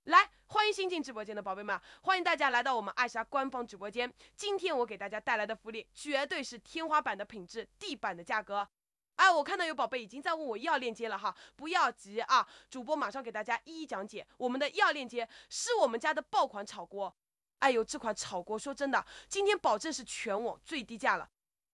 • 能够精准模拟真人的讲话语气、停顿等细节
以下是三种场景下真人音色与AIGC音色的对比：
电商直播_AIGC
电商直播_AIGC_demo.wav